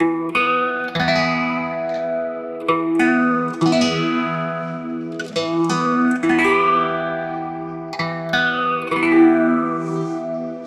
Index of /DESN275/loops/Loop Set - Drums- Downtempo Loops & Samples/Loops
RootOfUnity_90_E_AcousticGuitar01.wav